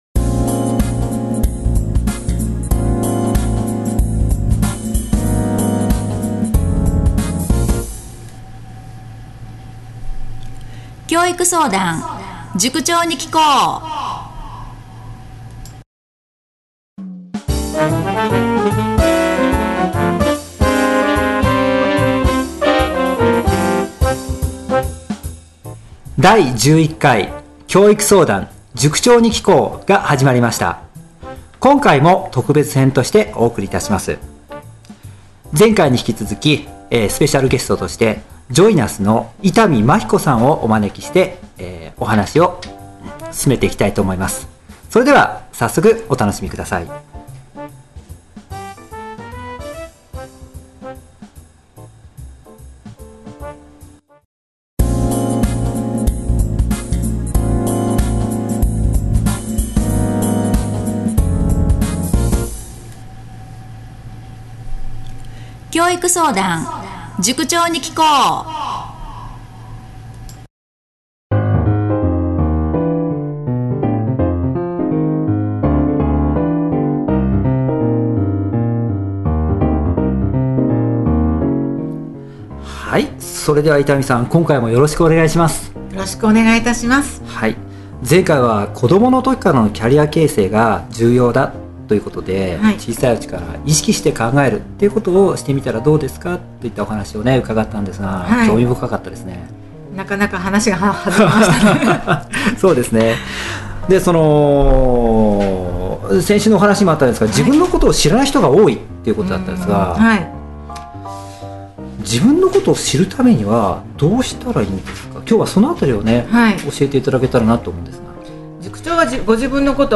教育相談 塾長に訊こう ポッドキャスト質問フォーム https